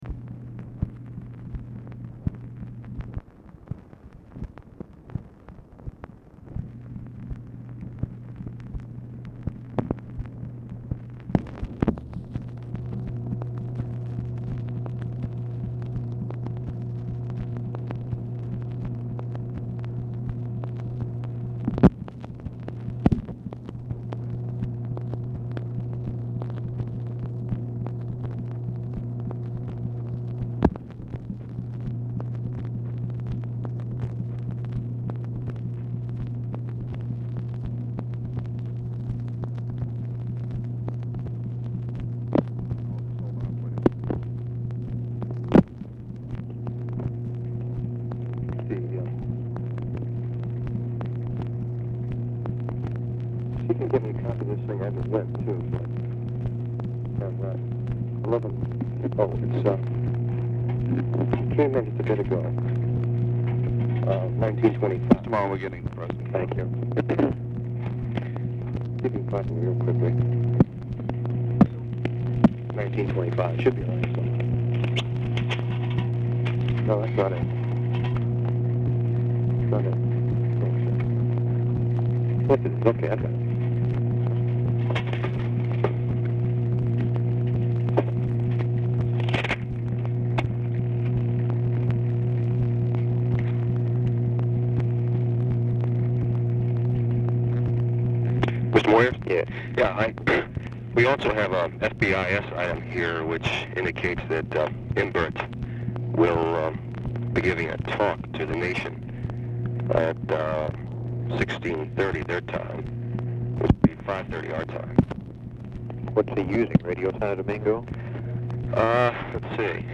Format Dictation belt
Camp David, Catoctin Mountain Park, Maryland